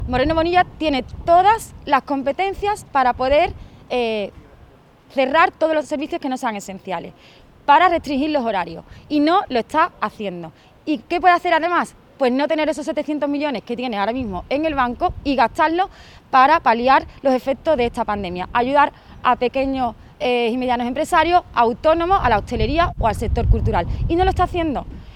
En declaraciones a los medios en Córdoba, Velarde ha exigido a Moreno Bonilla que “saque del banco” los 700 millones de euros de superávit “que vienen del Gobierno central” y se los gaste en reforzar la atención primaria y en ayudas a pequeñas y medianas empresas, hostelería, comercios y sector cultural.
Audio-_-Martina-Velarde-700-millones-de-superavit.mp3